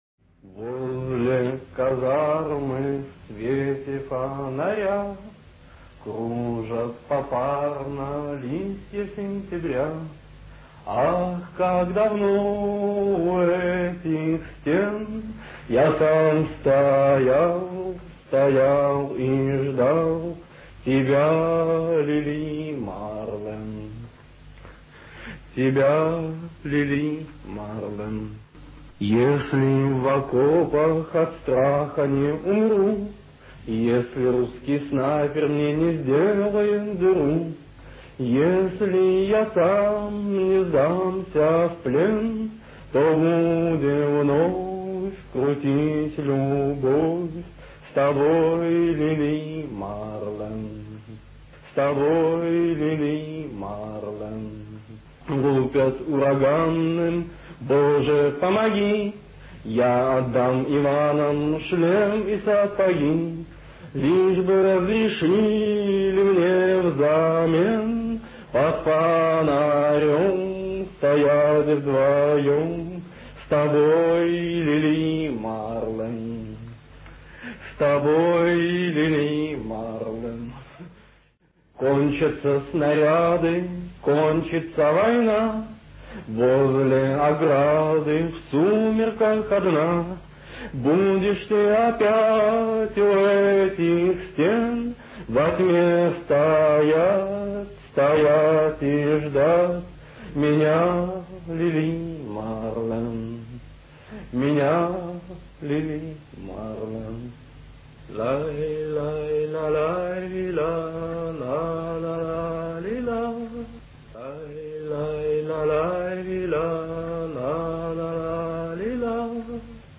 Кстати, послушайте пение того самого перевода Бродского в исполнении автора.